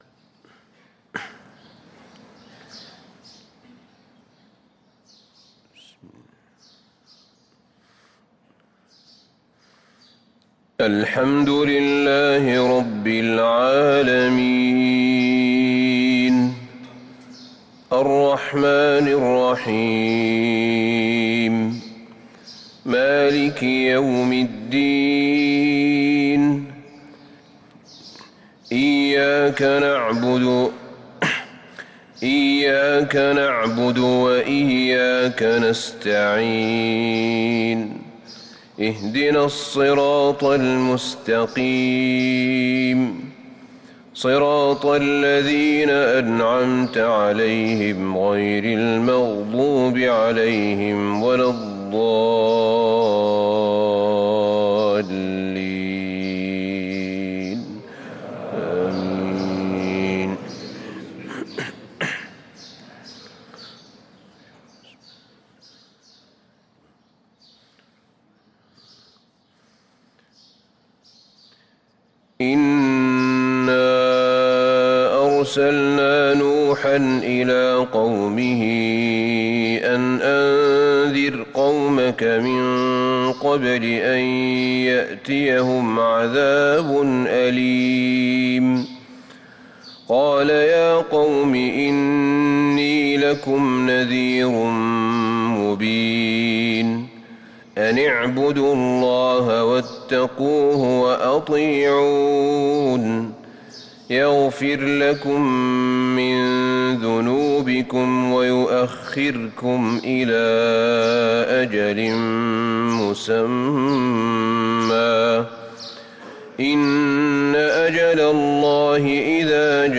صلاة الفجر للقارئ أحمد بن طالب حميد 25 جمادي الآخر 1445 هـ